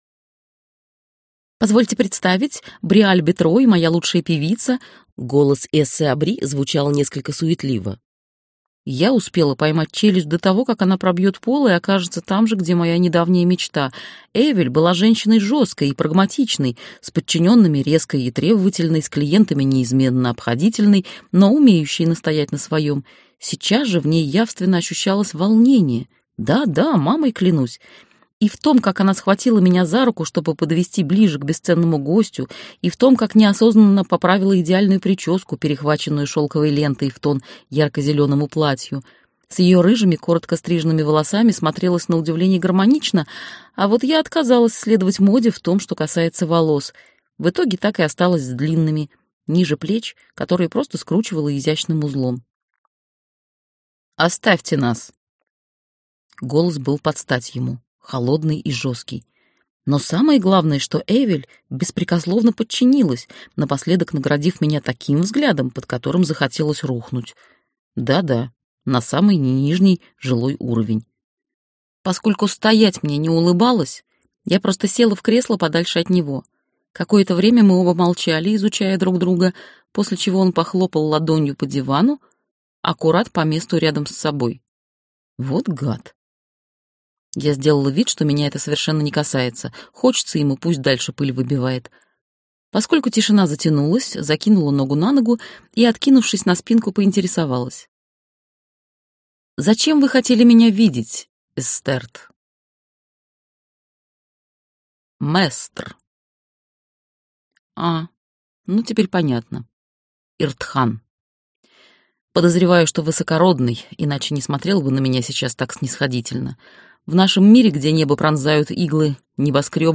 Аудиокнига Поющая для дракона - купить, скачать и слушать онлайн | КнигоПоиск